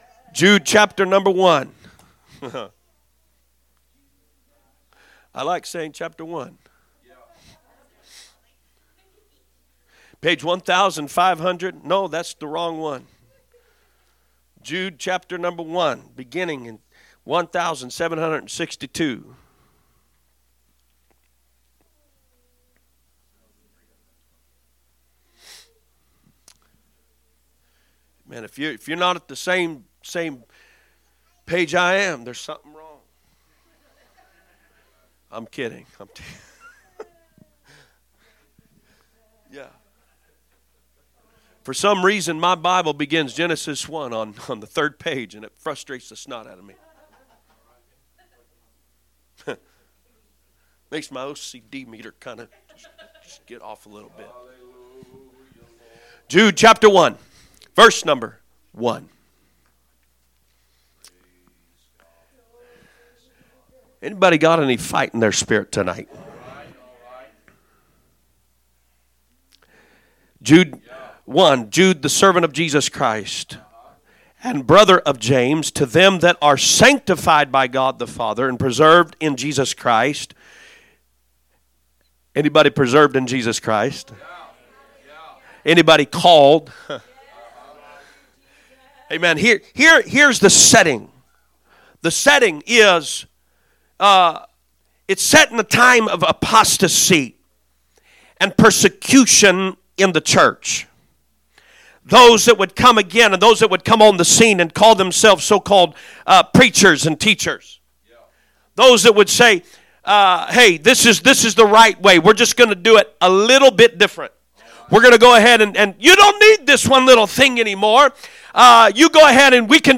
Message
A message from the series "Guest Speakers." 5/21/2025 Wednesday Service